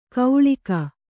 ♪ kauḷika